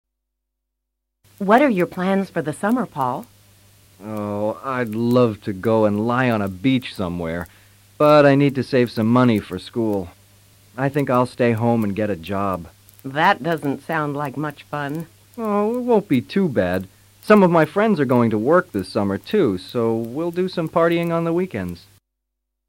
Esta sección, dividida en tres diálogos, presenta diferentes formas de vacacionar.